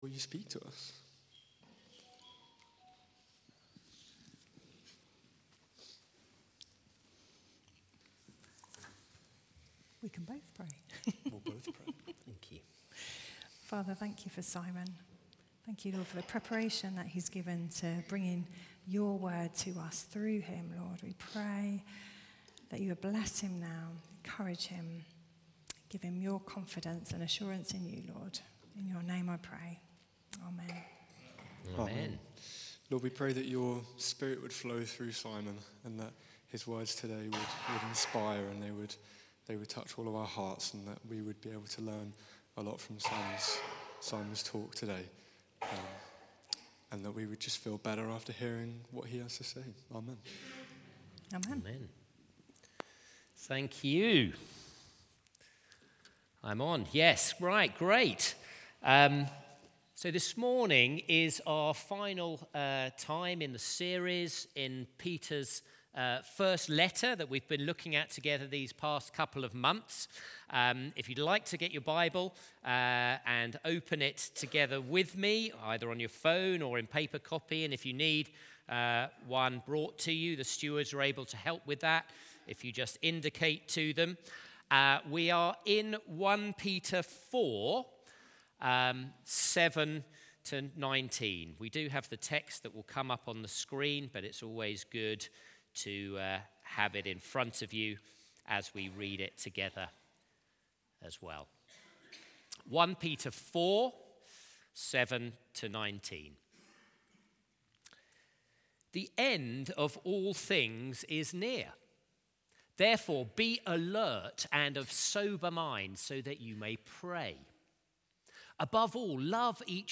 Sunday Service
Confident in Community Sermon